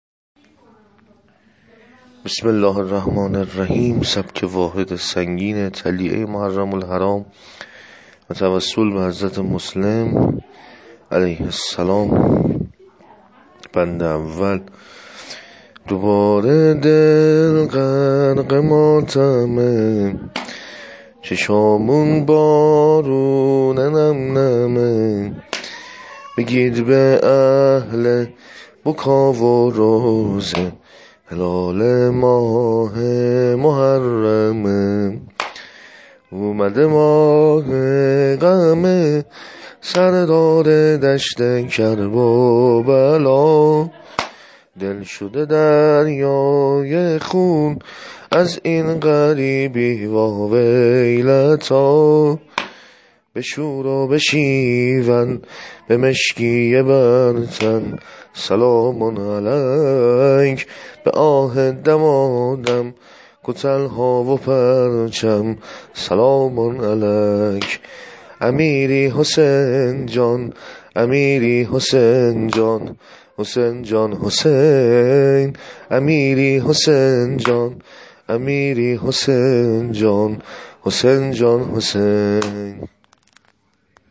دانلود متن و سبک نوحه روز عاشورای حسینی (ع) و وداع -( مهلا مهلا ای برادرم )